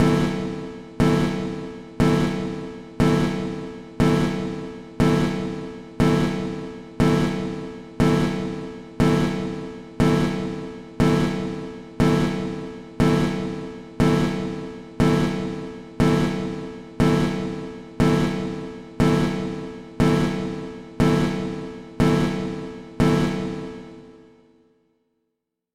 alarm_3.mp3